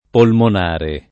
polmonare [ polmon # re ] agg.